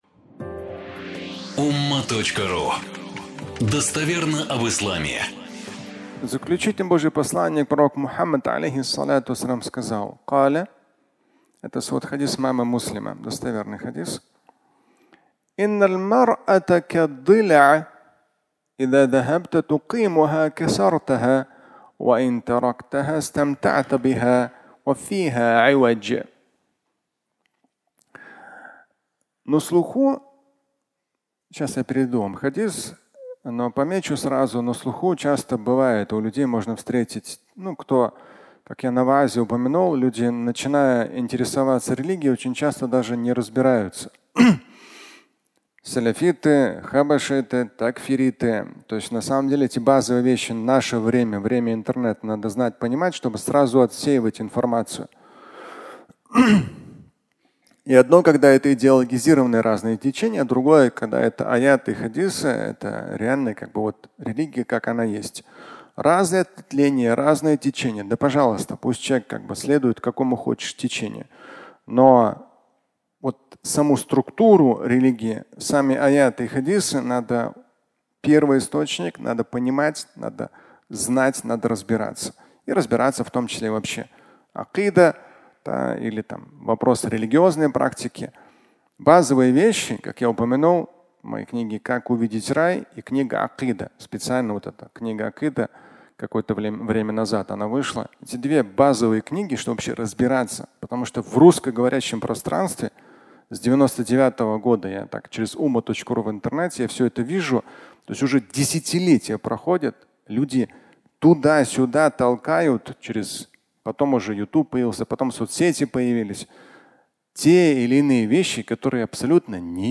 «Женщина из ребра» (аудиолекция)
Фрагмент пятничной проповеди